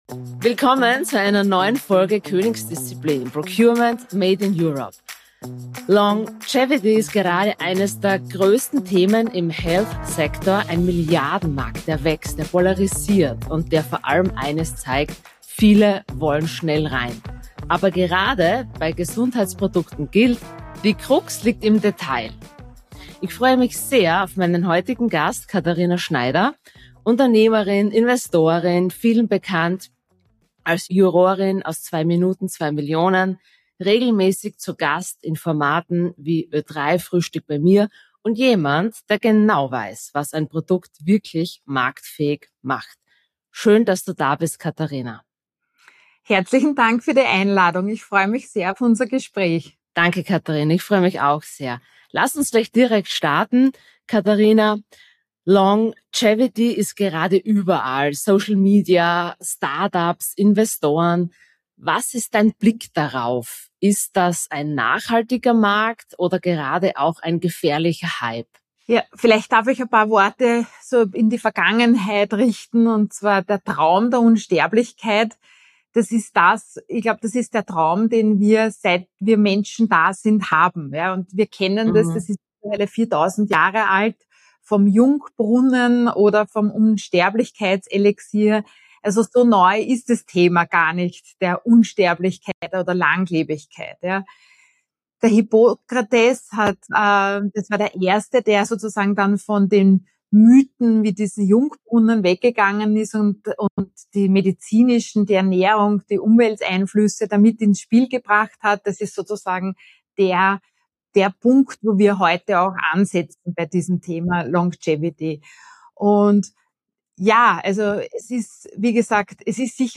Katharina Schneider, Unternehmerin, Investorin und bekannte Jurorin aus 2 Minuten 2 Millionen, spricht in dieser Folge Klartext. Sie erklärt, warum Longevity kein Thema für schnelle Kopien ist, was Studien, Zertifizierungen und Herkunft mit Vertrauen zu tun haben – und mit welchem realistischen Budget Gründerinnen und Gründer im Health-Bereich rechnen müssen.